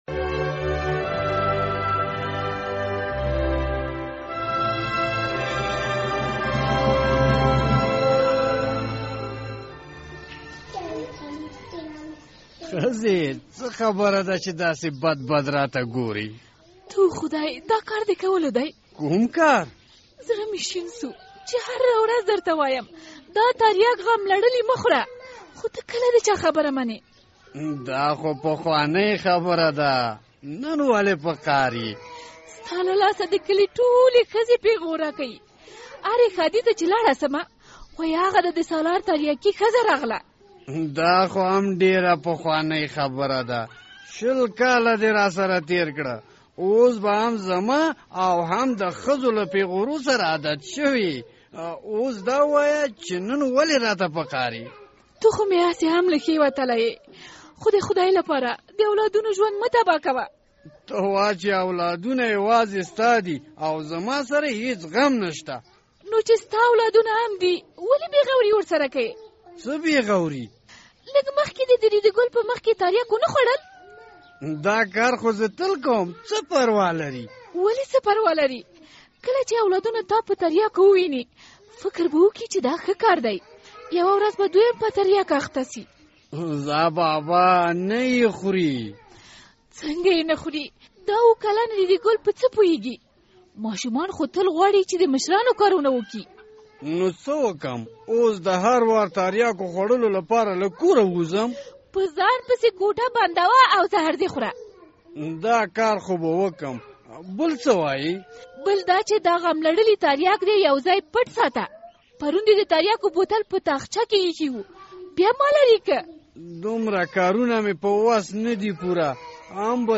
د زهرو کاروان پروګرام ډرامه